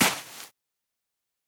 farlands_step_sand.2.ogg